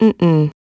Ripine-Vox-Deny.wav